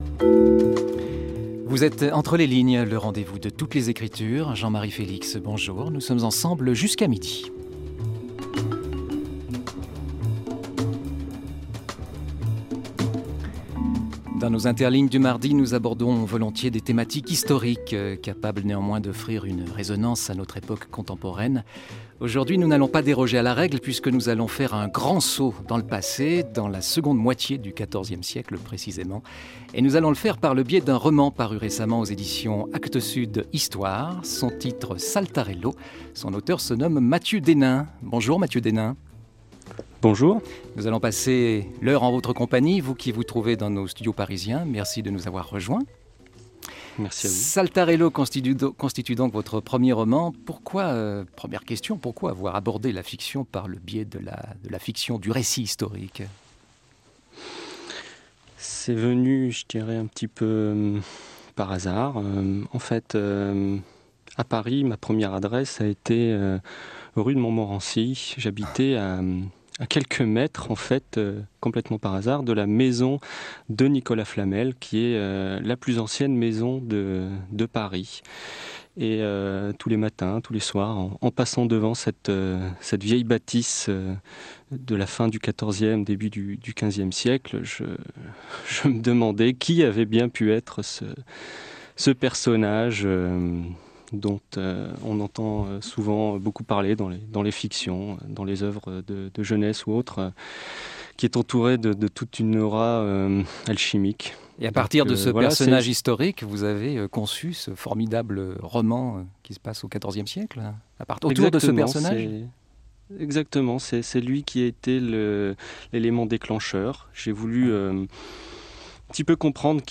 La discussion fut entrecoupée de lectures et d'extraits musicaux de saltarelles médiévales.